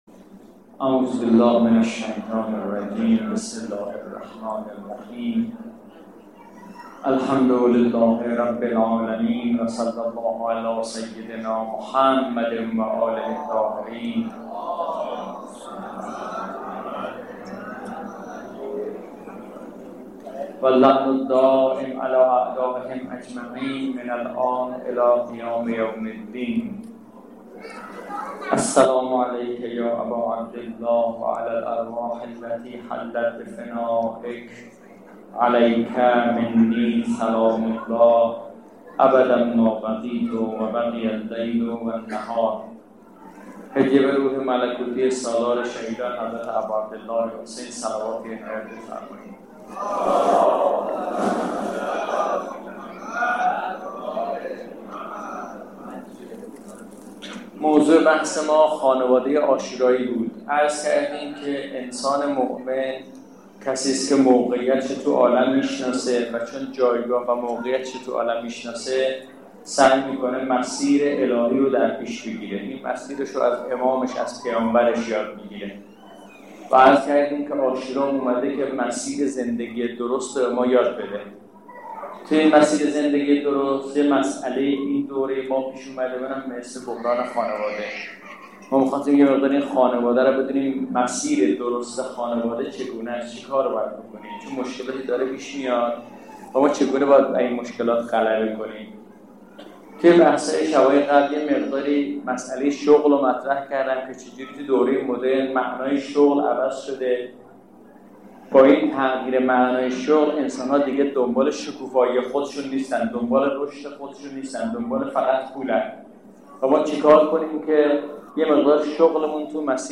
سخنرانی‌های